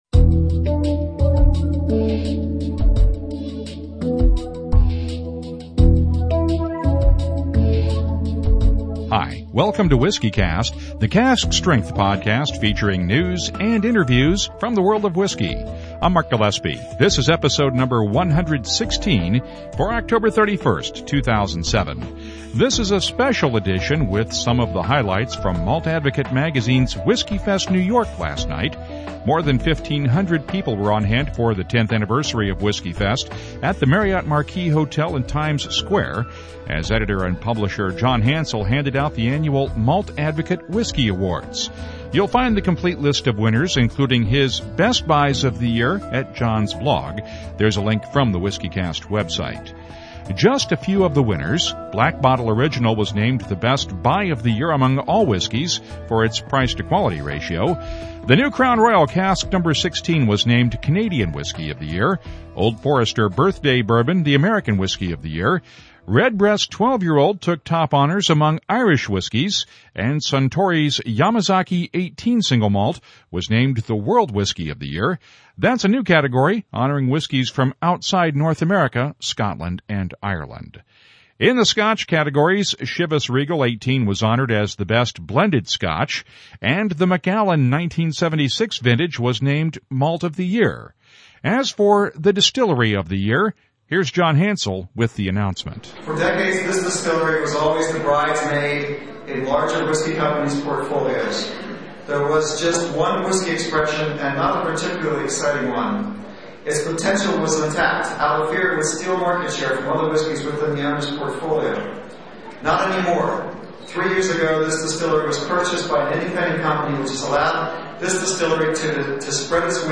This is a special episode of WhiskyCast with highlights from Tuesday night’s Malt Advocate WhiskyFest in New York City.